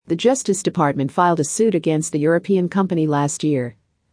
ディクテーション第1問
【ノーマル・スピード】